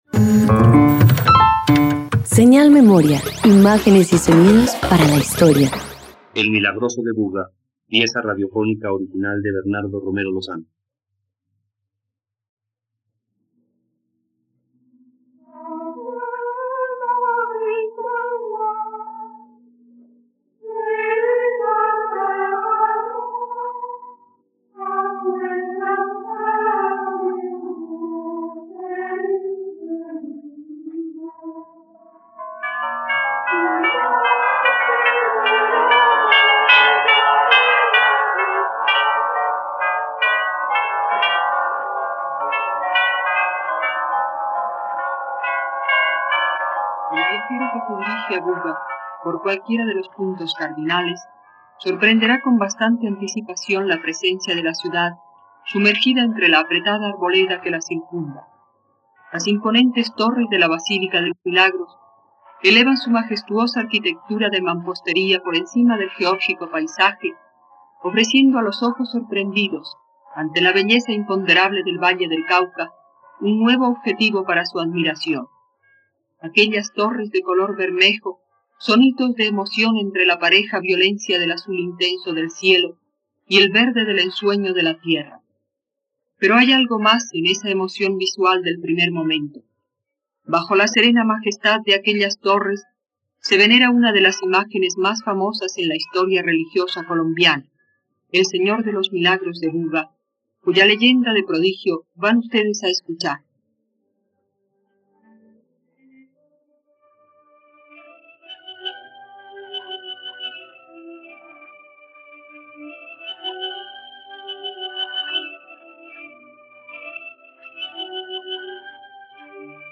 ..Radioteatro. Escucha la adaptación radiofónica de “Milagroso de Buga” de Bernardo Romero Lozano por la plataforma streaming RTVCPlay.
La historia narra el "Milagroso de Buga", una imagen de Jesús crucificado que creció milagrosamente y se convirtió en objeto de devoción en Colombia. Este relato se presenta con música, efectos de sonido y un elenco teatral en la Radiodifusora Nacional de Colombia